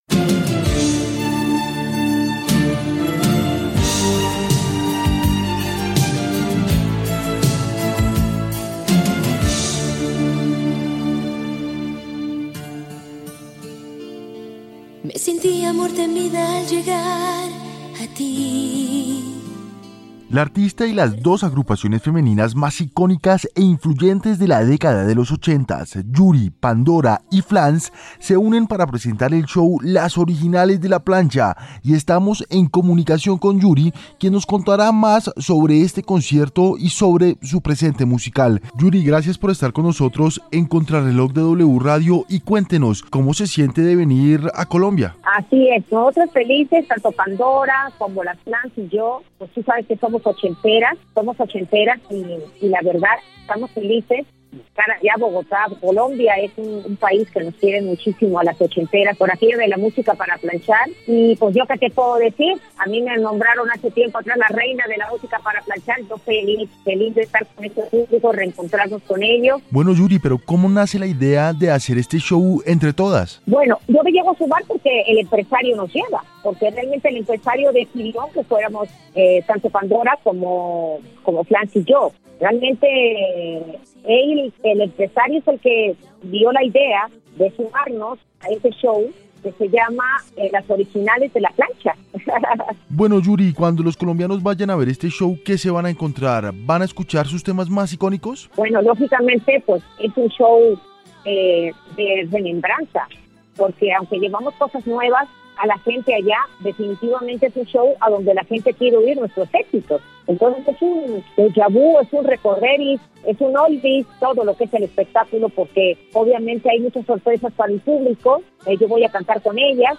En diálogo con Contrarreloj de W Radio, Yuri dio detalles de su show ‘Las originales de la plancha’, el cual se realizará el próximo 20 de agosto en el Centro de Eventos Autopista Norte de Bogotá.